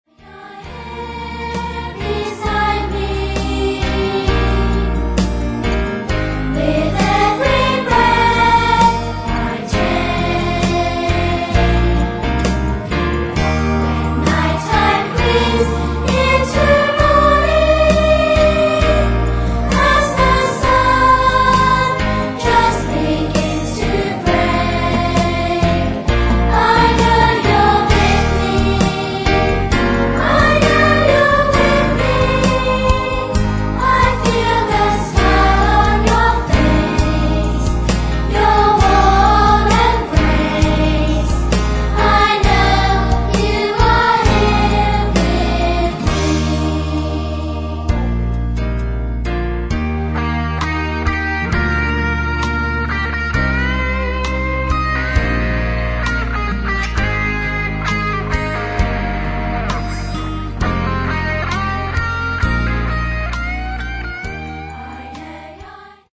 is an up-beat musical for Keystages 1 & 2